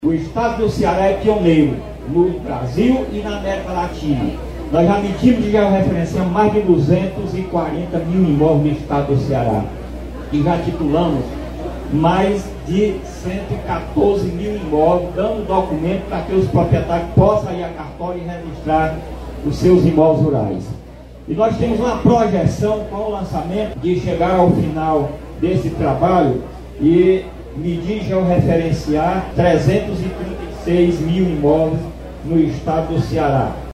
O superintendente do Idace, José Wilson Gonçalves, destacou o pioneirismo do Estado na política de regularização fundiária no Brasil.